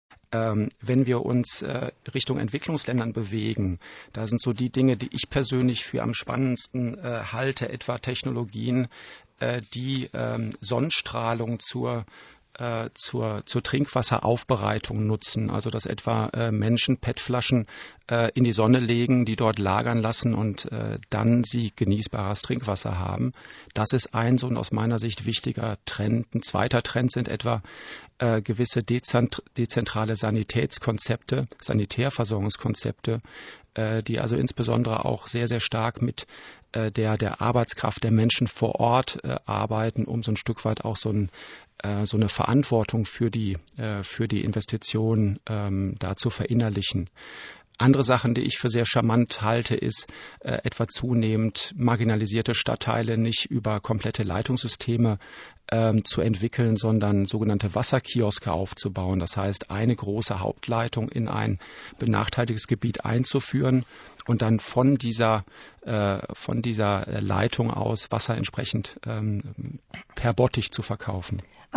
Hier das vollständige Gespräch